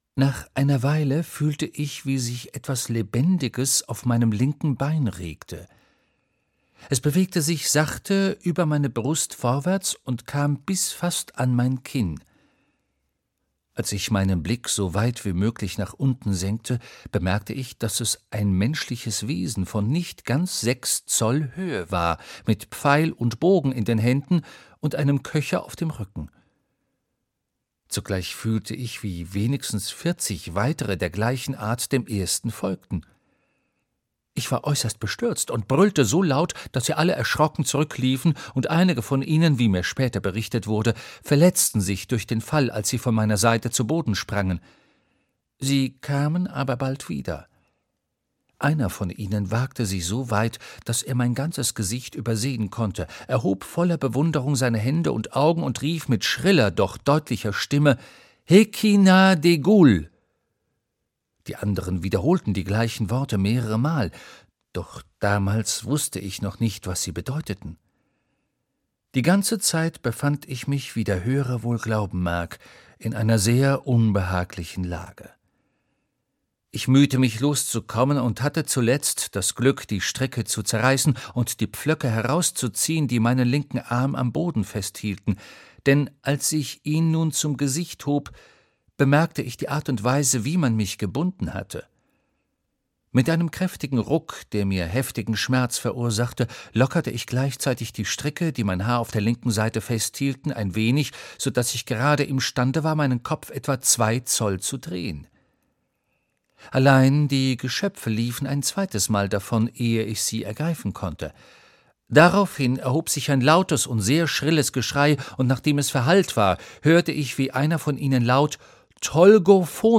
Gullivers Reisen - Jonathan Swift - Hörbuch